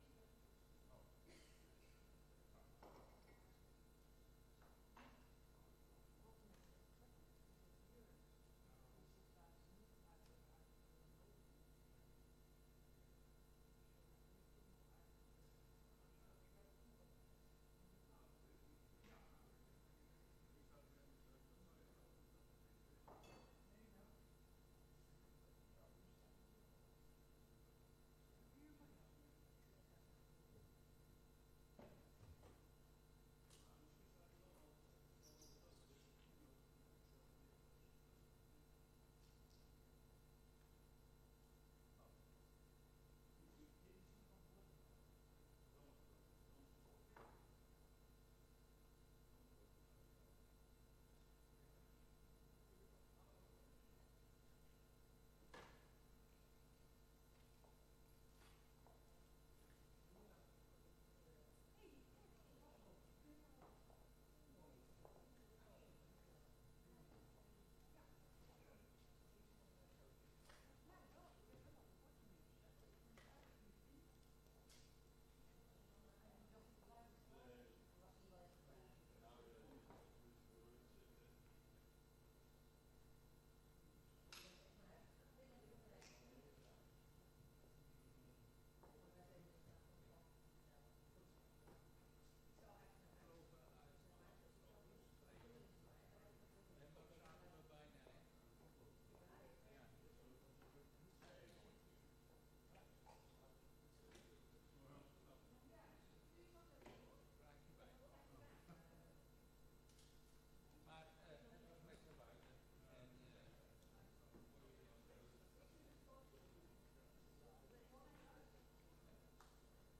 Toelichting:De zomernota is een tussentijdse rapportage in de Planning & Control-cyclus van 2025. De beeldvorming bestaat uit een presentatie over het actueel financieel kader en de afwijkingen met financiële impact. Na de presentatie is er gelegenheid tot het stellen van beeldvormende vragen.
Locatie: Statenzaal